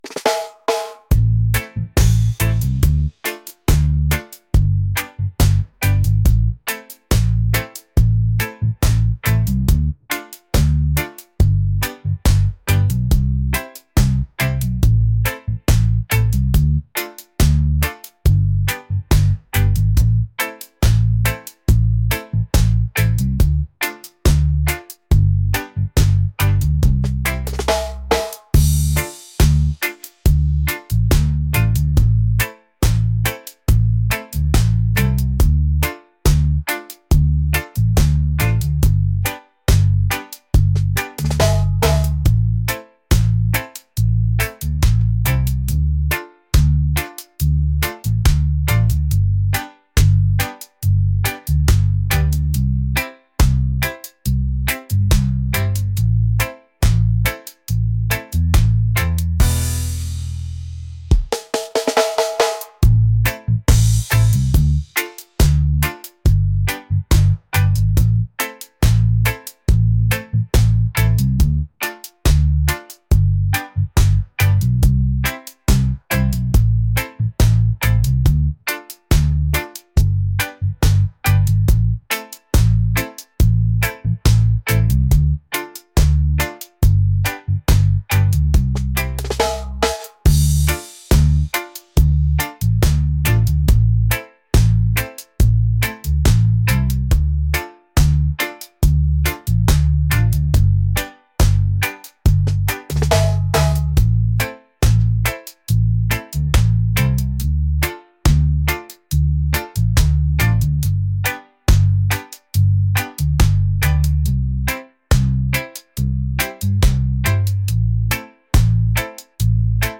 reggae | soul | laid-back